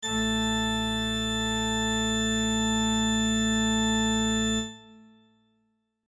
LA-110-a-5-octavas1.mp3